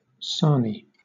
Ääntäminen
Southern England British: IPA : /ˈsɑːni/